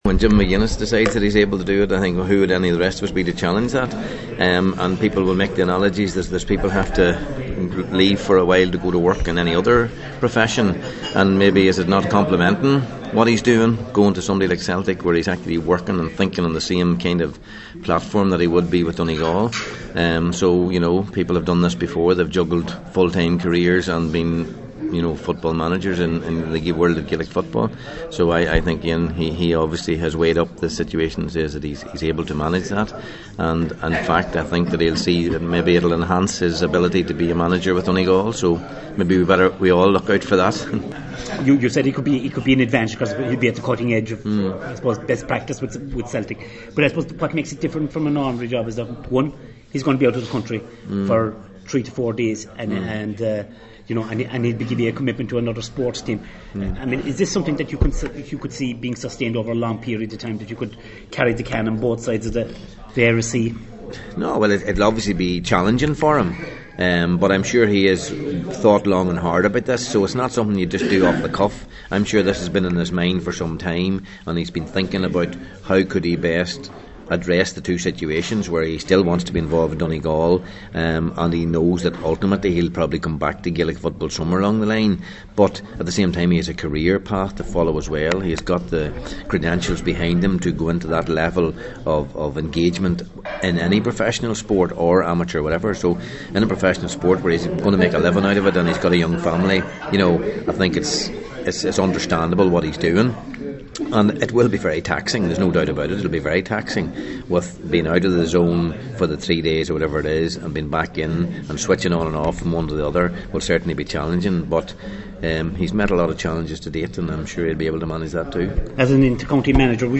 Tyrone Manager Mickey Harte feels Donegal will benefit from McGuinness’ involvement with Celtic…